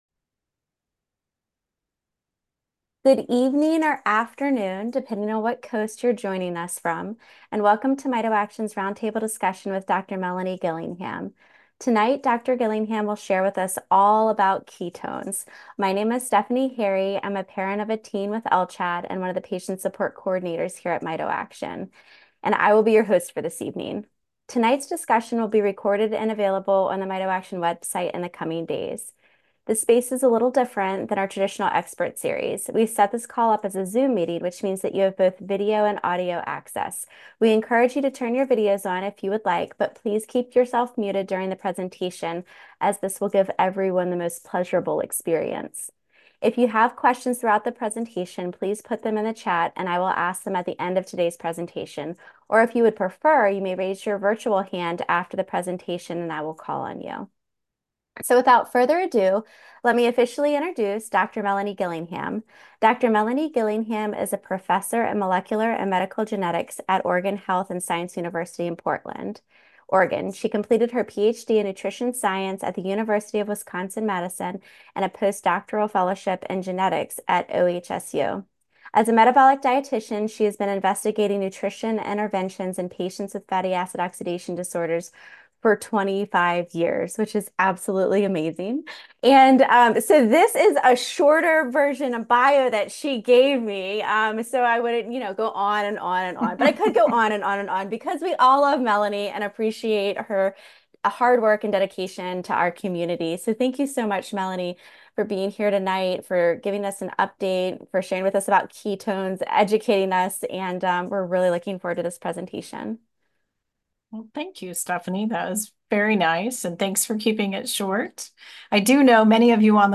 This presentation will go over what ketones are, how ketones are made in the body and how ketone supplements might be a little different. We will also discuss the current evidence that ketones might be a potential treatment option and what are the key unresolved questions about ketones that are limiting the field moving forward.